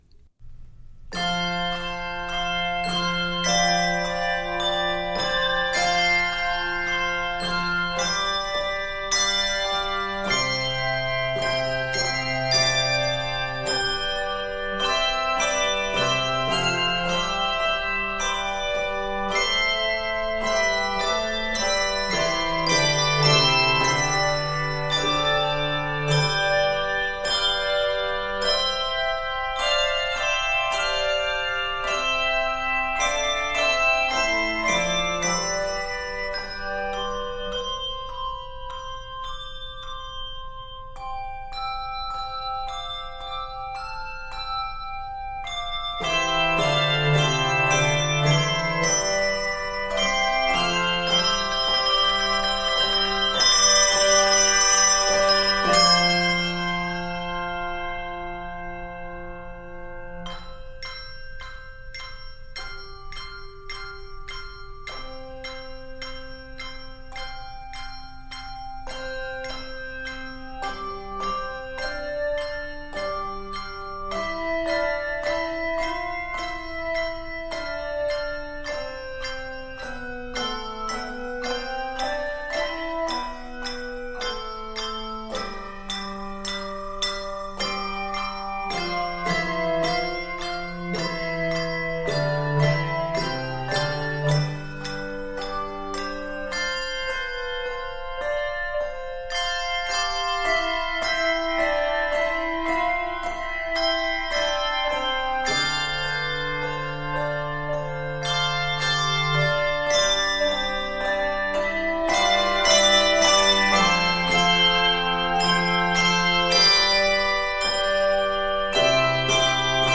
is 79 measures in the key of C Major
is 56 measures in the key of Bb Minor
is 51 measures in the key of G major
Octaves: 2-5